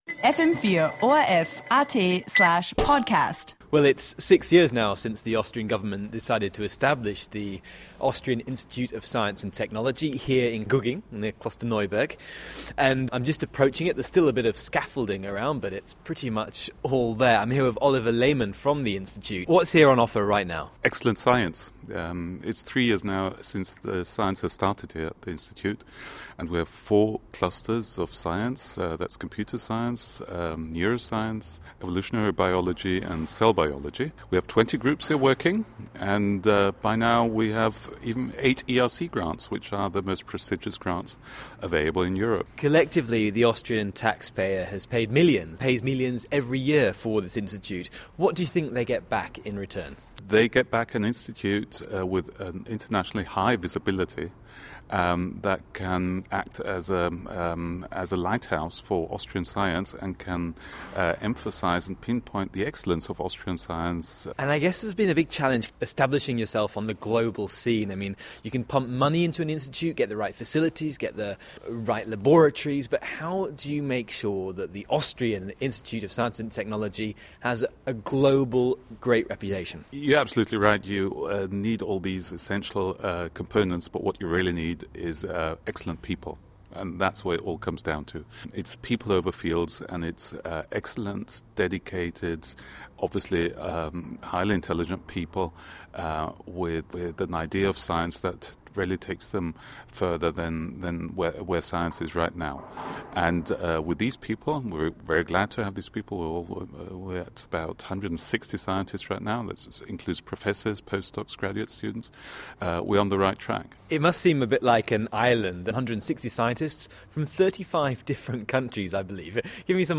Have a listen (except for the music that has been edited out for copyright reasons).